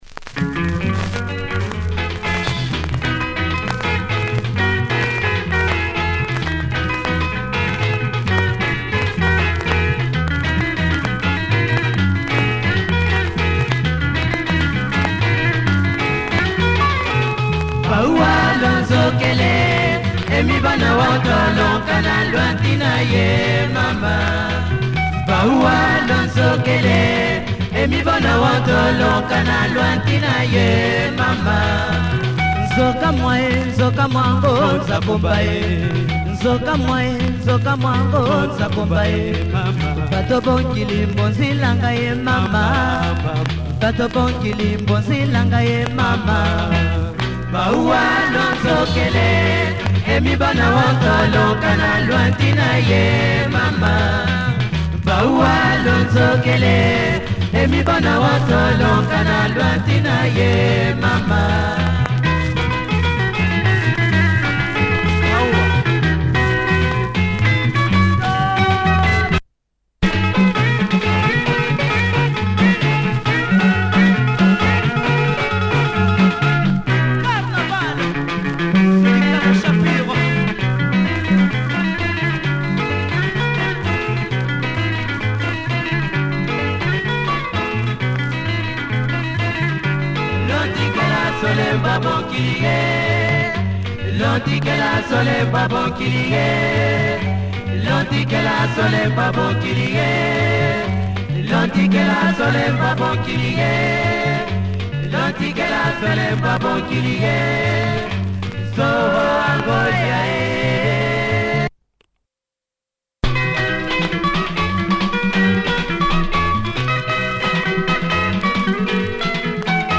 Nice Lingala track